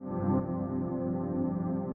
If we lower the decay to 0%, these are the results:
You can see how the volume drops drastically to sustain after the attack.